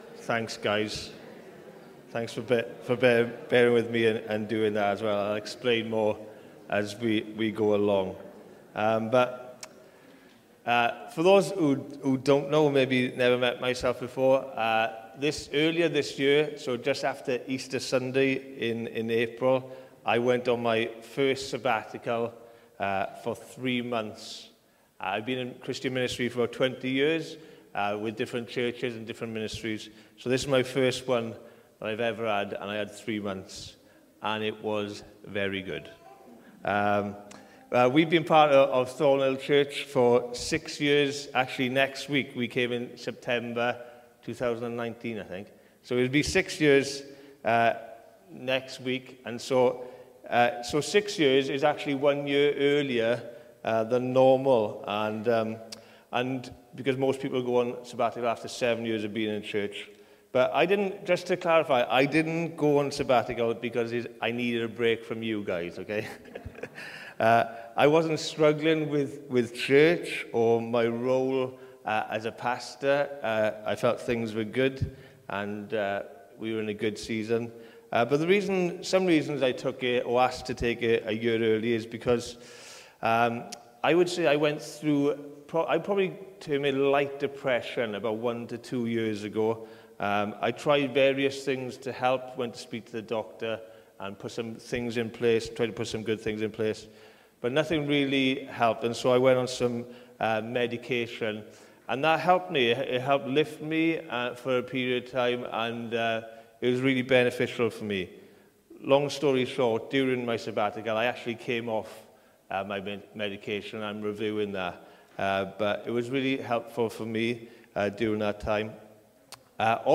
“Sabbatical Sermon”